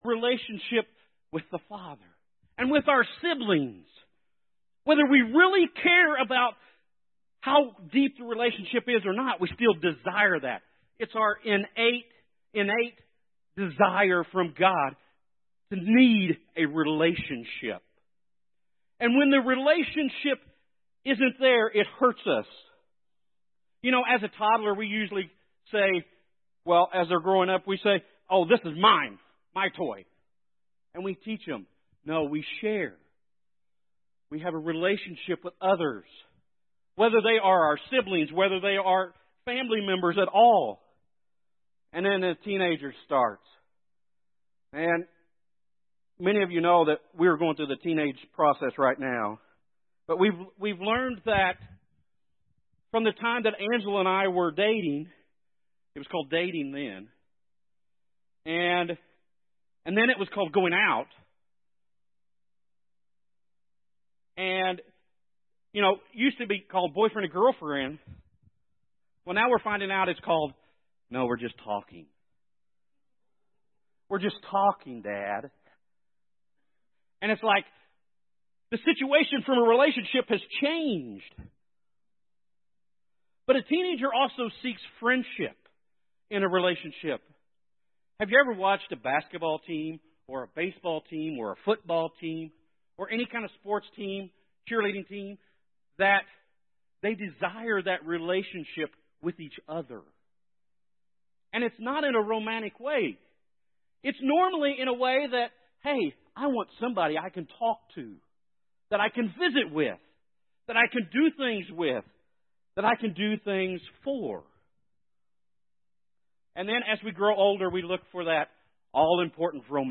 Loving God with All Your Heart January 21, 2018 Love Audio Sermon Save Audio What does it mean to love God with all your heart, soul, strength, and mind?